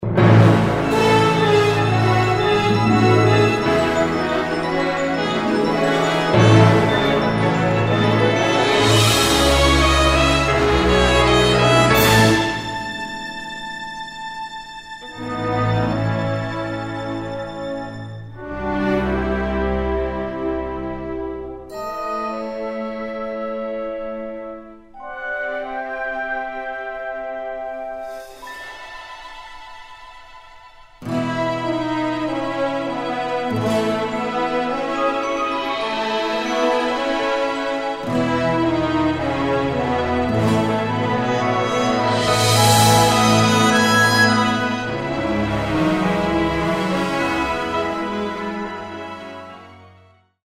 performance track
Instrumental
orchestral , backing track